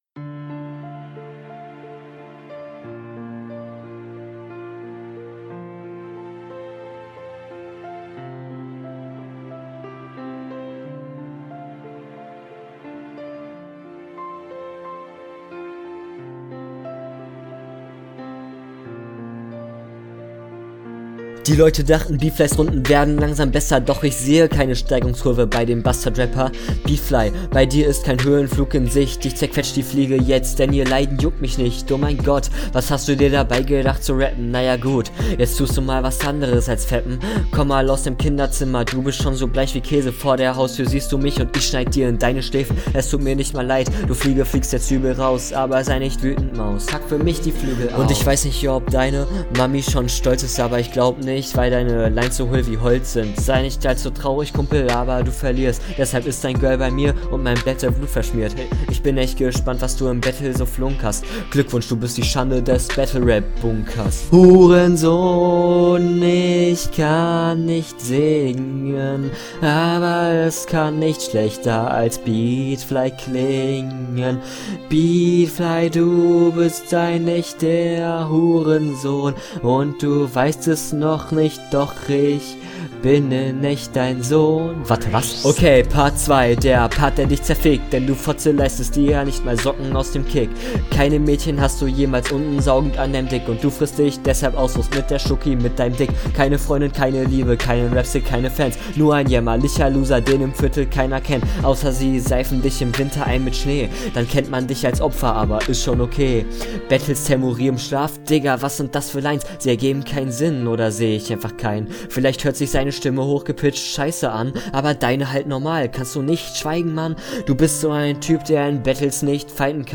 Trauriger Beat, hören was kommt.